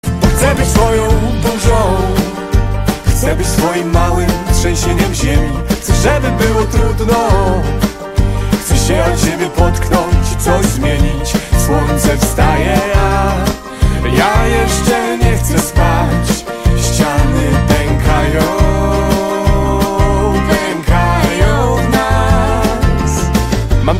Kategoria POP